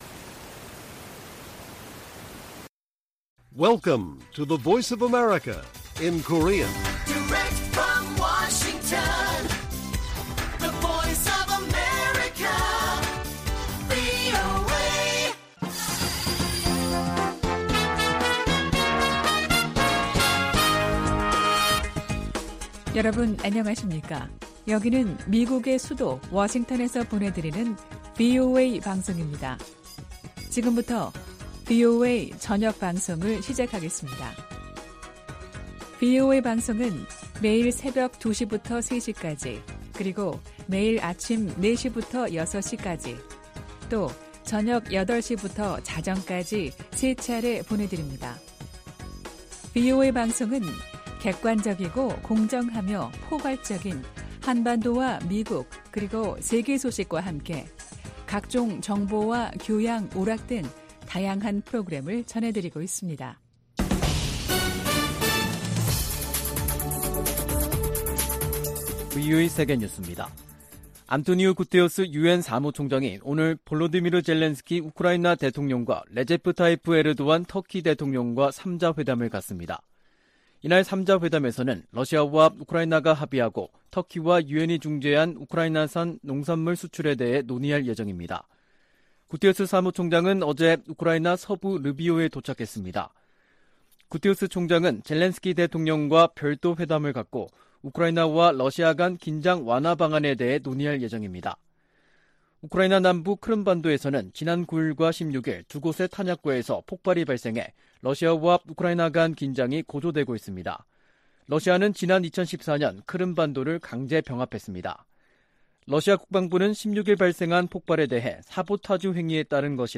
VOA 한국어 간판 뉴스 프로그램 '뉴스 투데이', 2022년 8월 18일 1부 방송입니다. 한국 정부가 ‘담대한 구상’과 관련한 구체적인 대북 메시지를 발신하고 북한이 수용할 수 있는 여건을 조성해나갈 것이라고 밝혔습니다. 이종섭 한국 국방부 장관이 서울 국방부 청사에서 폴 나카소네 미국 사이버사령관을 접견하고 북한 등 사이버 위협에 대해 협력 대응하기로 했습니다. 미 국무부는 북한의 도발적 행동에 변화가 없다면 제재는 계속될 것이라고 밝혔습니다.